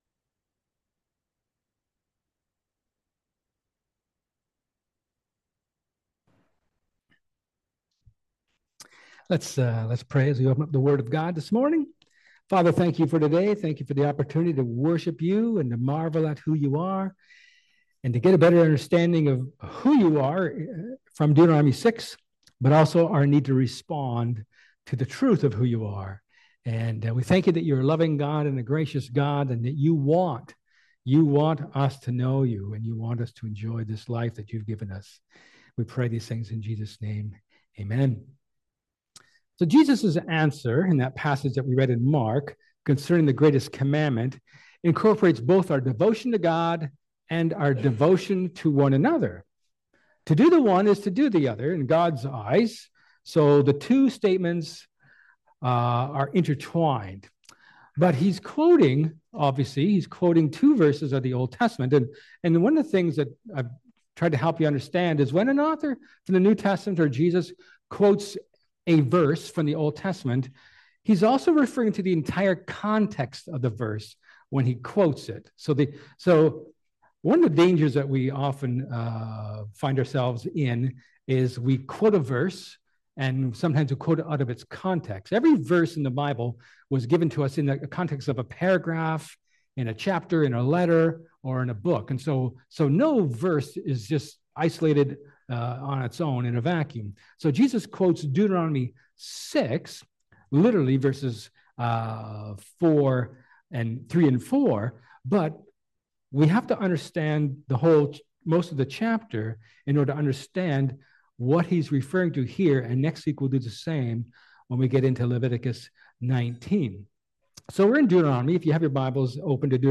Jan-5-2025sermon.mp3